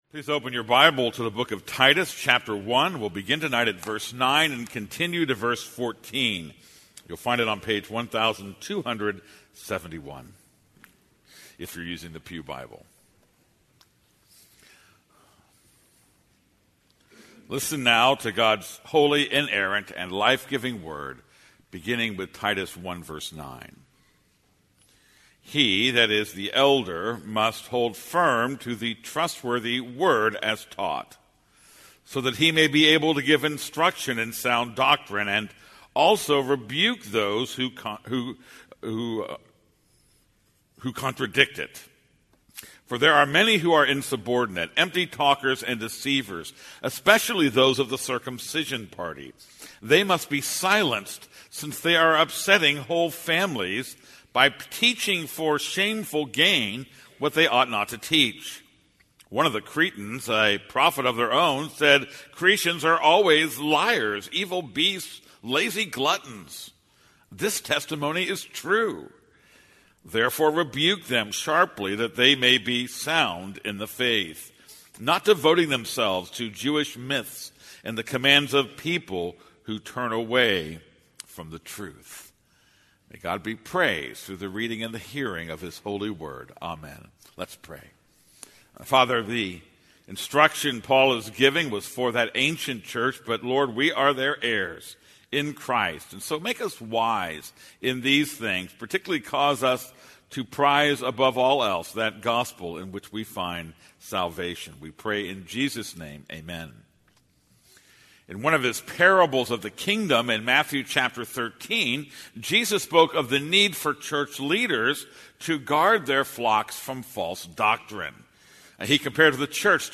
This is a sermon on Titus 1:9-14.